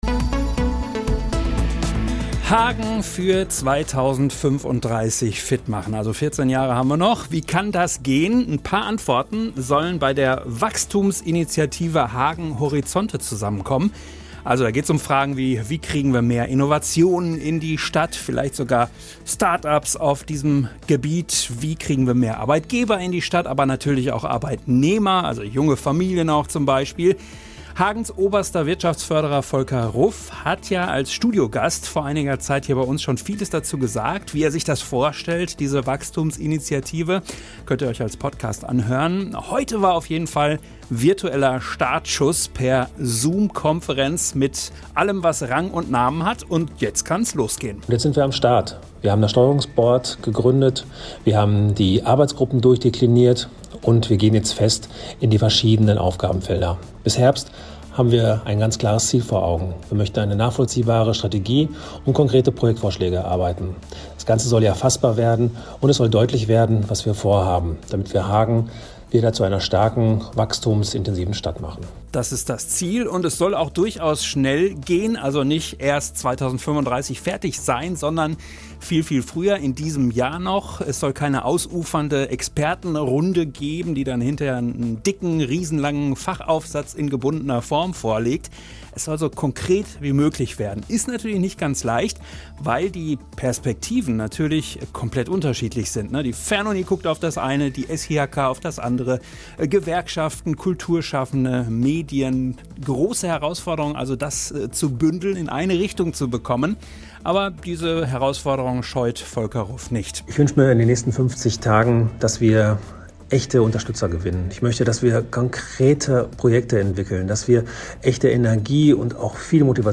Anzeige Anzeige MITSCHNITT AUS DER SENDUNG play_circle Abspielen download Anzeige